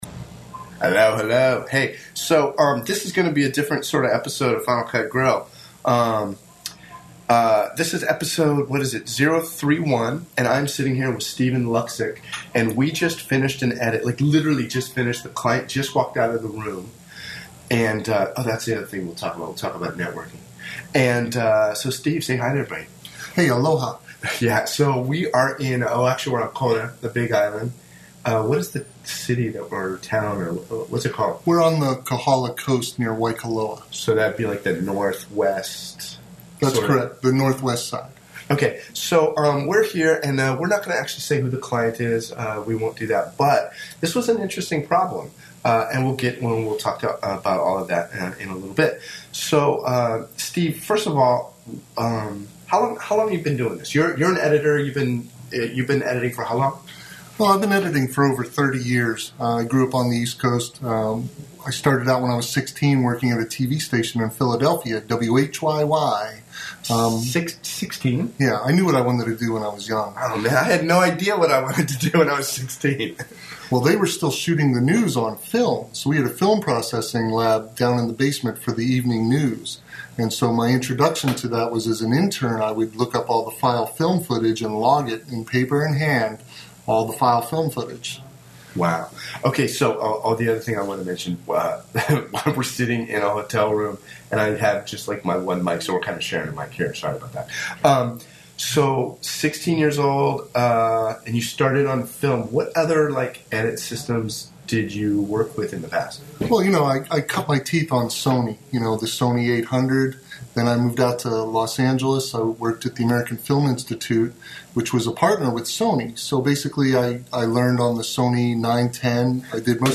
Excuse the audio quality, we only had one mic and had to share it.